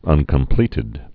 (ŭnkəm-plētĭd)